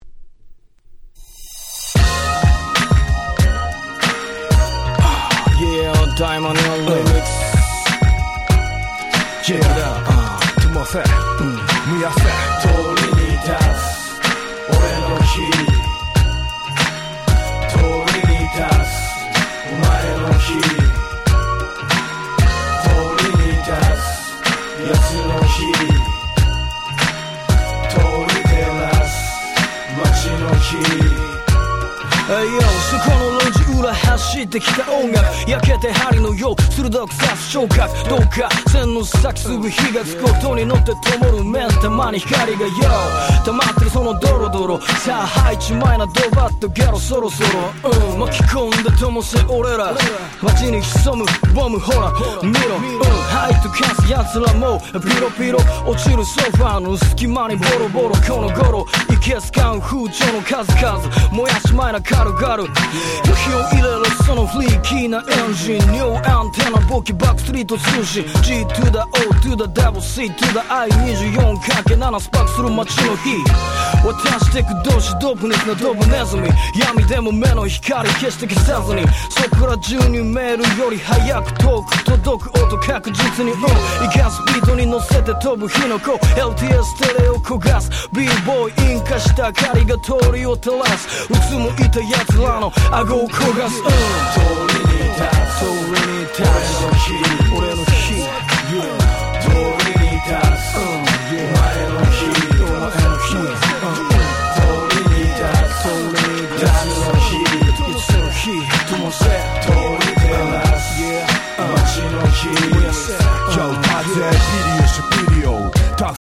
02' Japanese Hip Hop Classics !!
J-Rap 日本語ラップ